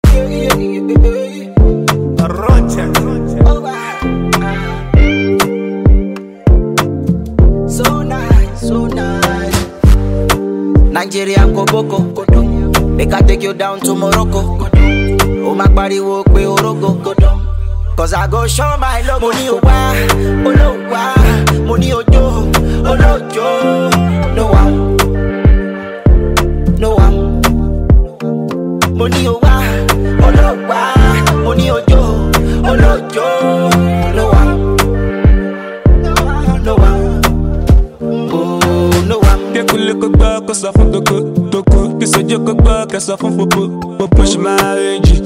filled with energy, melody and captivating sound